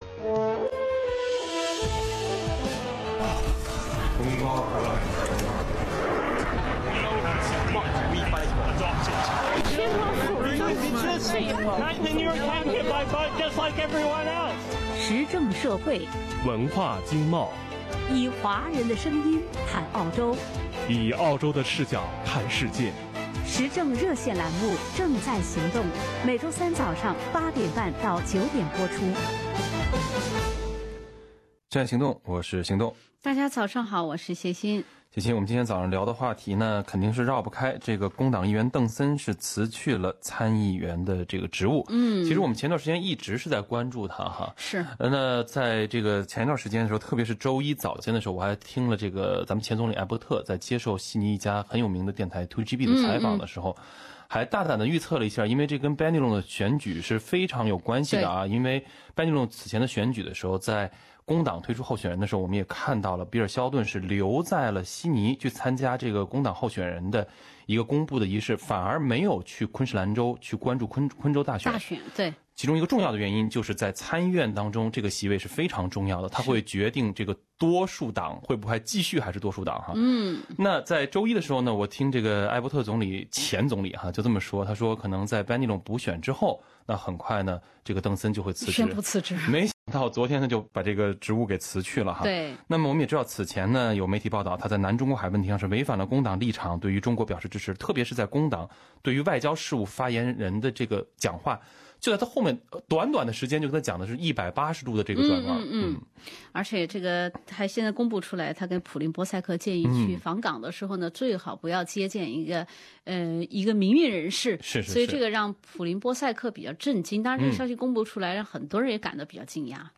本期《正在行动》节目中，听众朋友们就此话题表达了自己的观点 （听众和网友观点不代表本台立场） 。